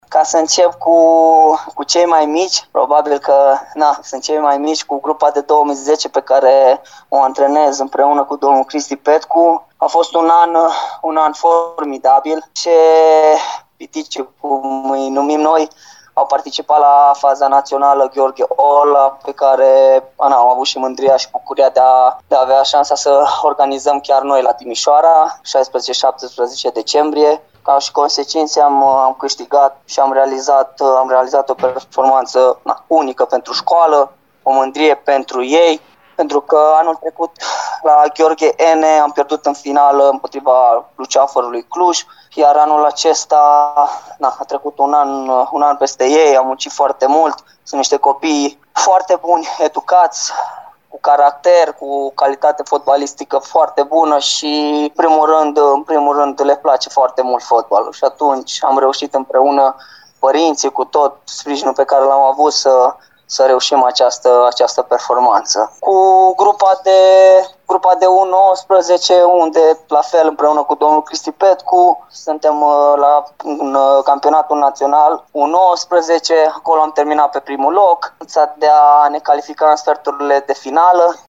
Într-un interviu